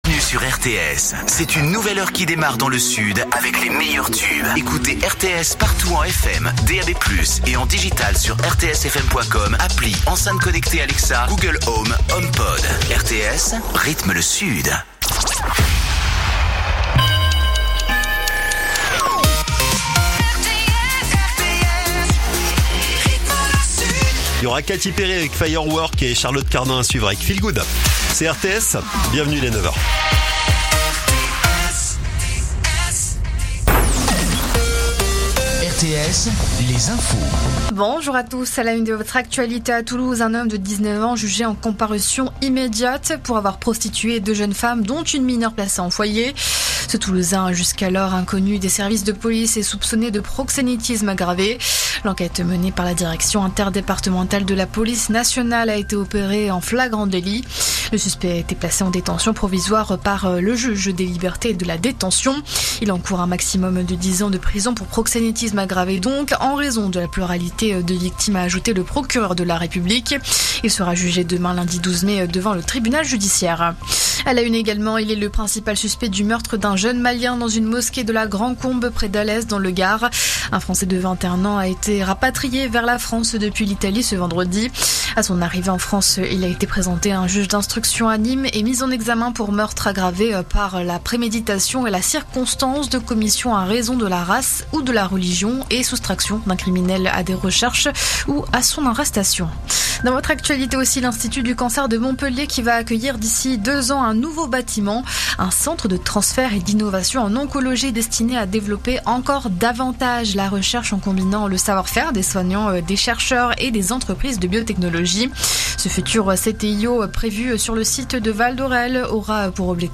info_narbonne_toulouse_380.mp3